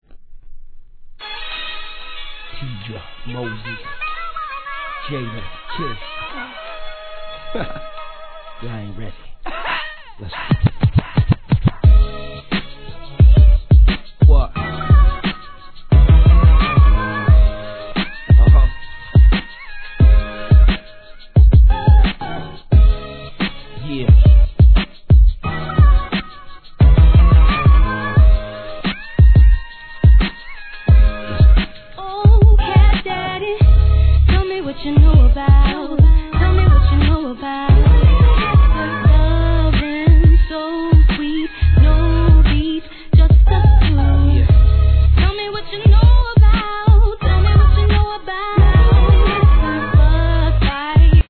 HIP HOP/R&B
45回転サンプリングを効果的に用いたドリーミ-なトラックに